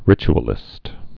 (rĭch-ə-lĭst)